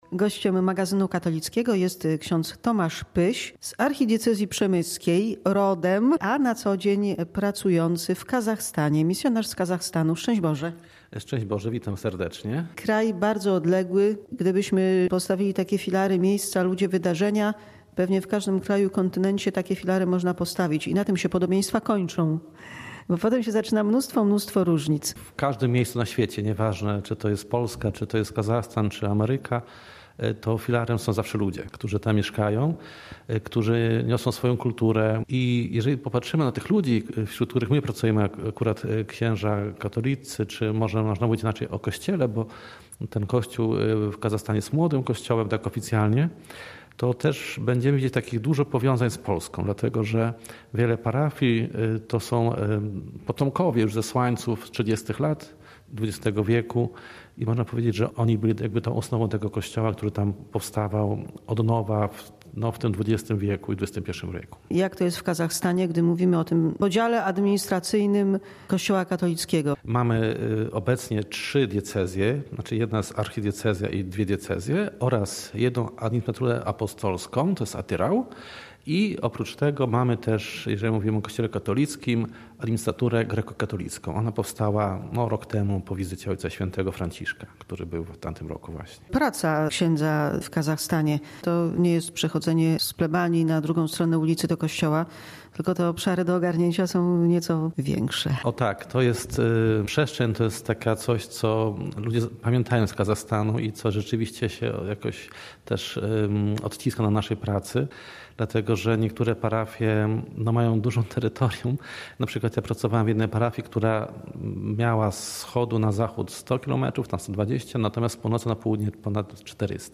Kierunek: Kazachstan. Rozmowa z misjonarzem